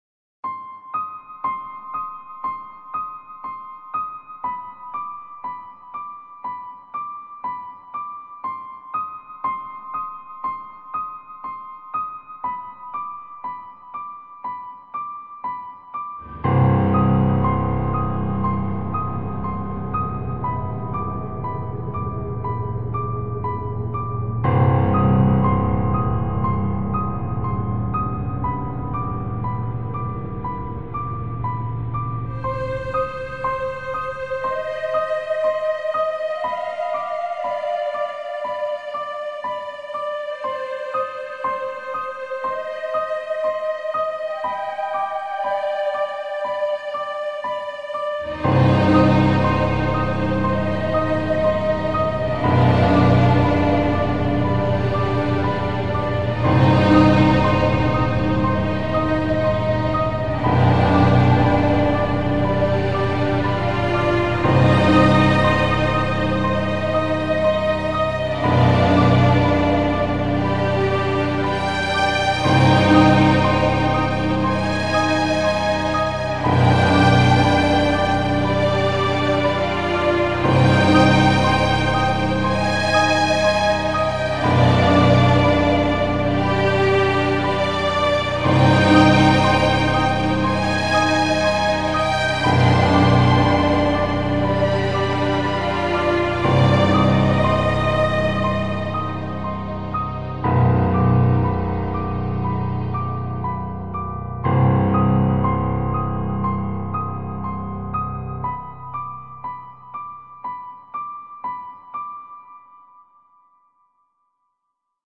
Очень_страшная_музыка.mp3